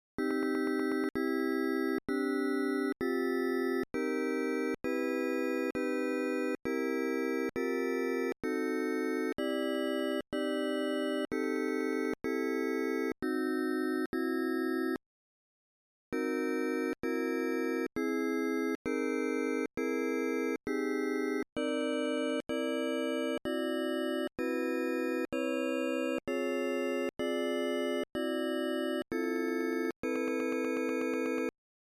Single cycle chord Pack for Elektron Sampler